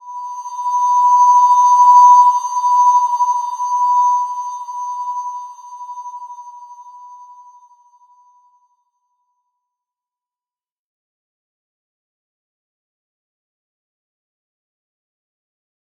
Slow-Distant-Chime-B5-f.wav